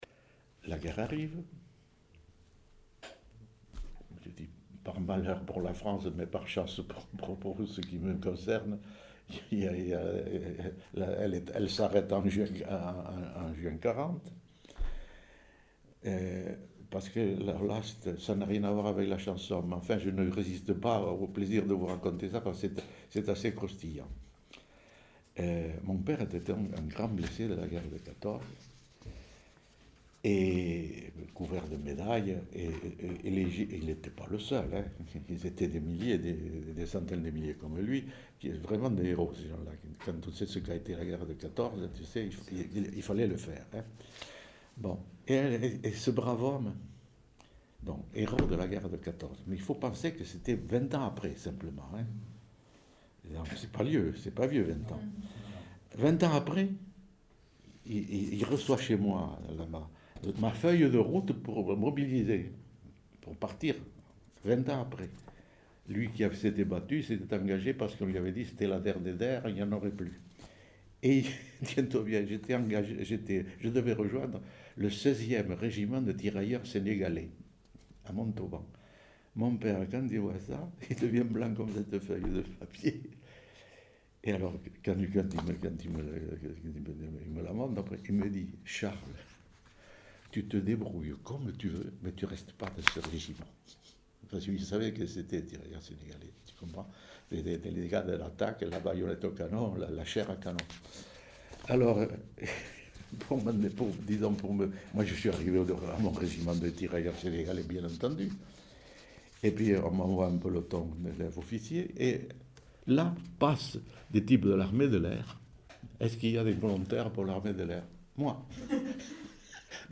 Aire culturelle : Rouergue
Genre : récit de vie